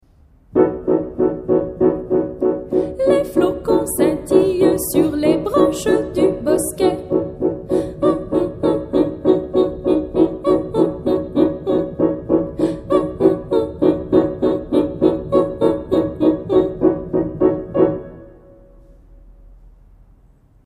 Audio canon de l'hiver mélodie de la chute